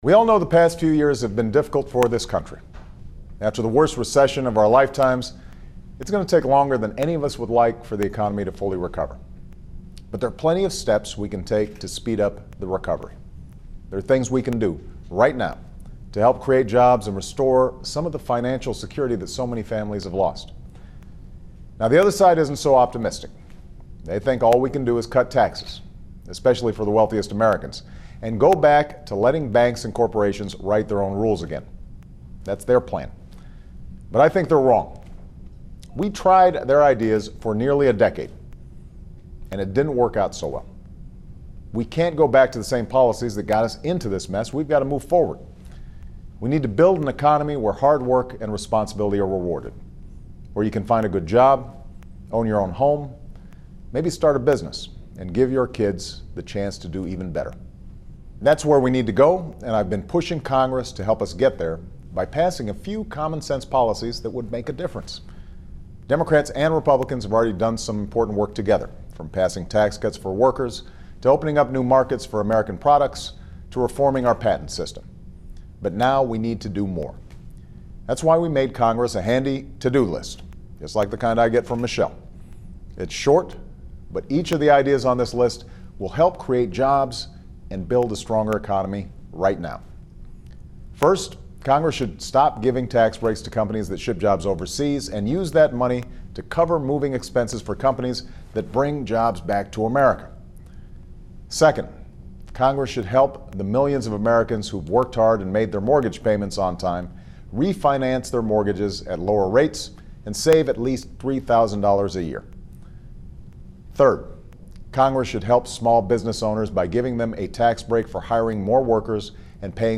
• Weekly Address: Congress Must Act on "To-Do List"